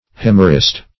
Euhemerist \Eu*hem"er*ist\, n. One who advocates euhemerism.